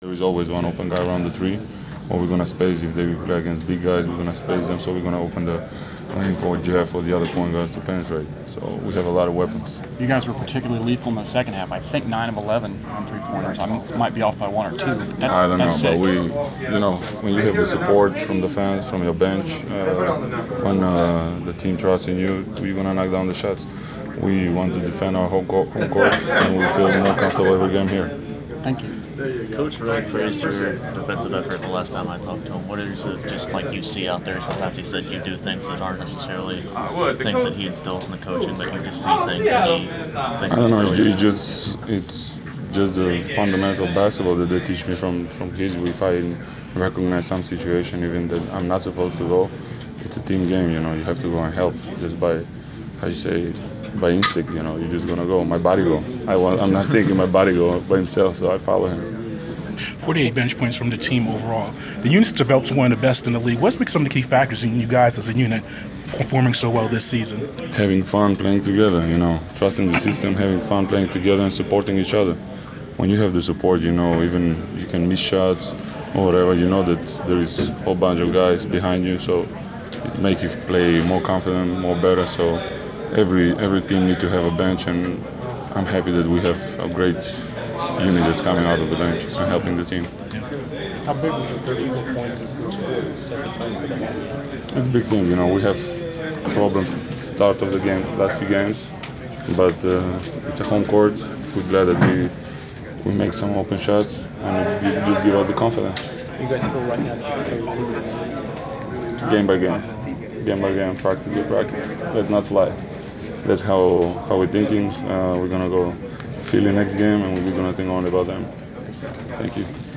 Inside the Inquirer: Postgame presser with Atlanta Hawks’ Pero Antic (1/11/15)
We caught up with Atlanta Hawks’ forward Pero Antic following his team’s 120-89 win over the Washington Wizards on Jan. 11. Topics included the Hawks’ offense, the play of the bench and facing fellow European Marcin Gortat of Washington.